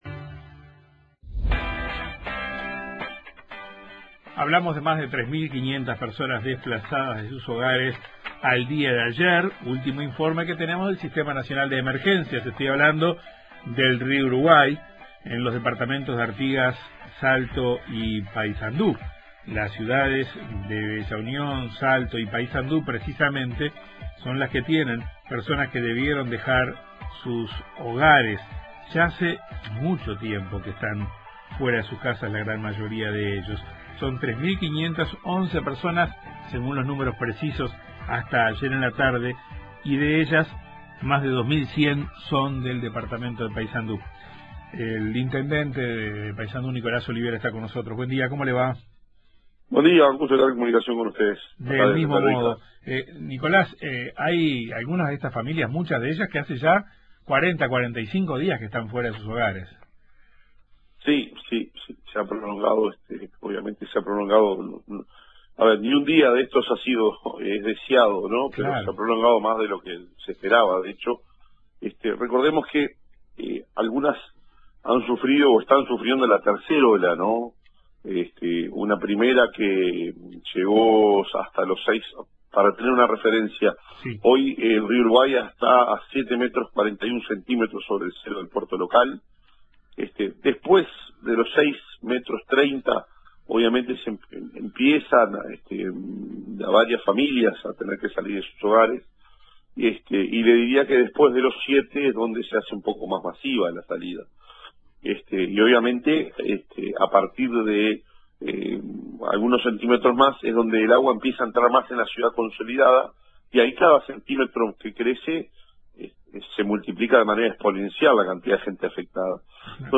El intendente de Paysandú, Nicolás Olivera, explicó en Informativo Uruguay que el nivel del río “sobre el cero del puerto” llegó a los 7.41 metros.